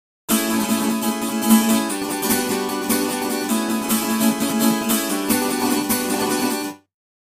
「Acoustic Guitar」
・アコギ （MP3 112KB）
（プリセット 「Country」 を２トラック使用）